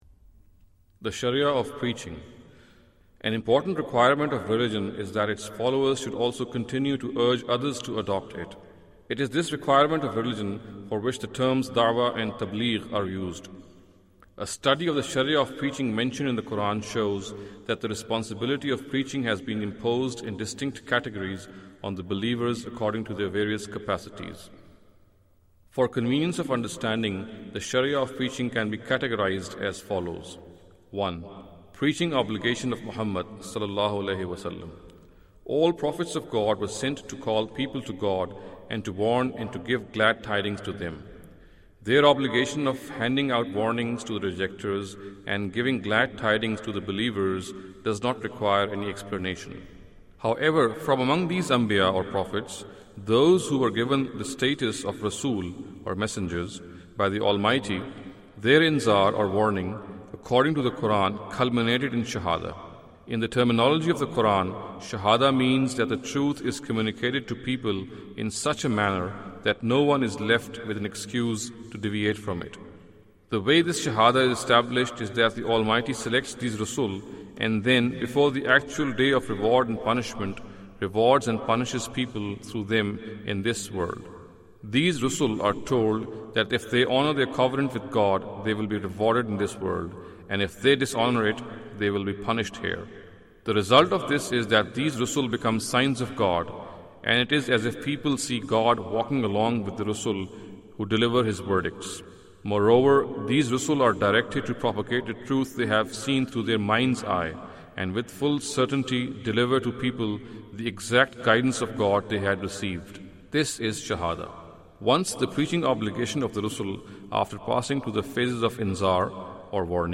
Category: Audio Books / Islam: A Concise Introduction /